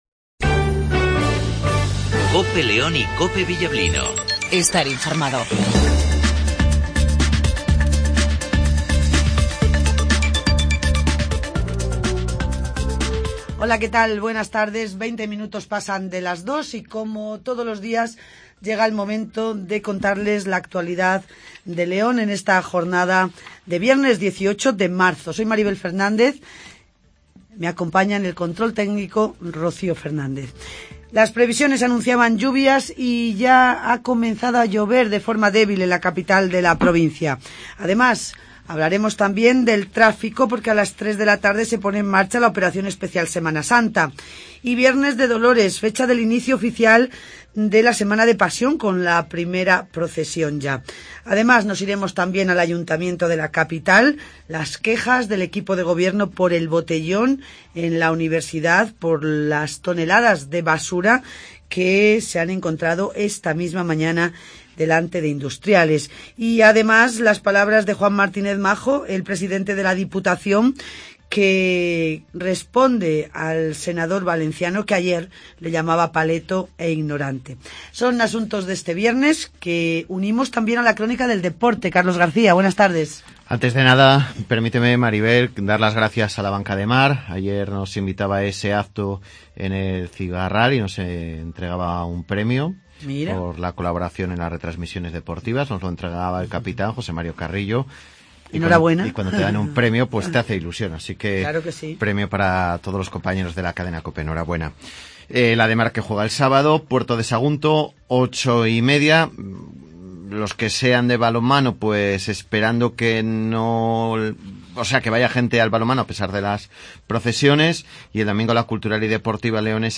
INFORMATIVO MEDIODIA